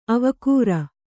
♪ avakūra